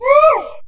All Types Of Vocal Pack